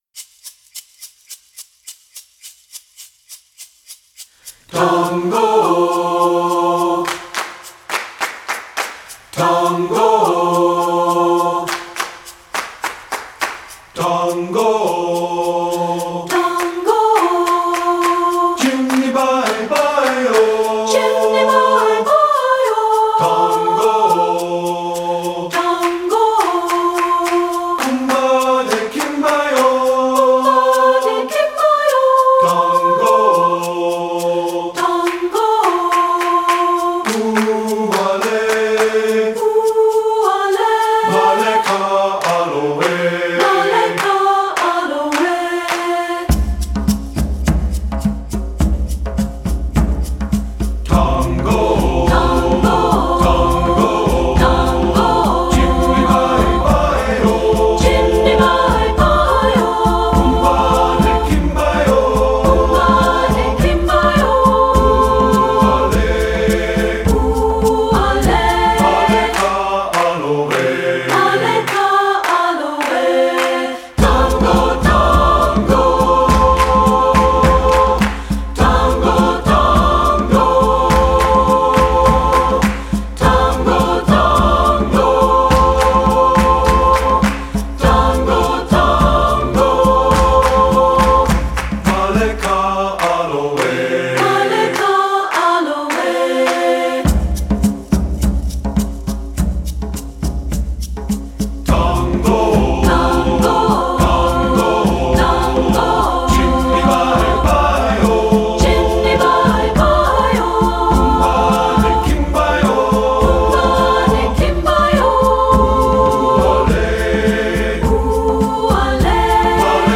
Voicing: SATB and Percussion